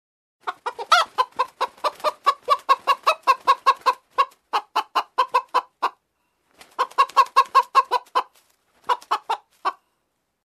Звуки куриц, петухов
На этой странице собраны разнообразные звуки куриц и петухов: от утреннего крика до квохтания наседки.